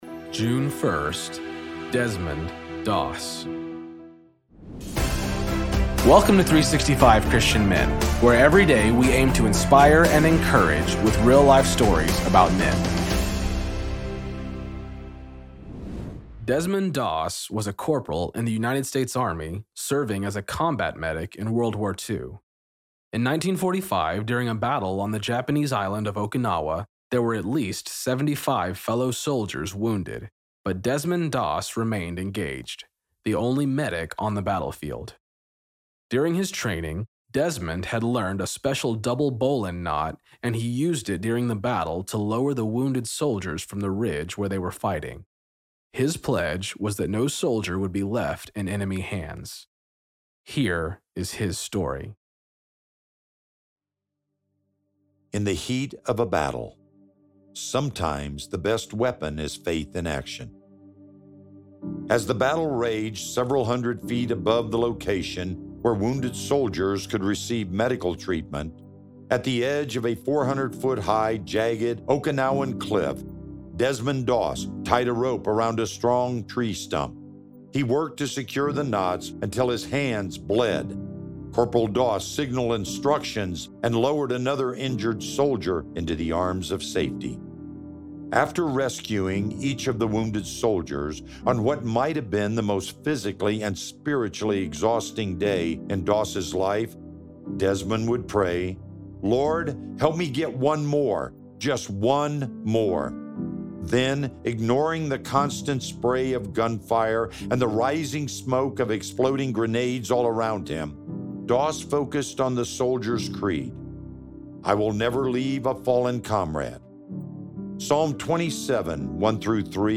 Story read by:
Introduction read by: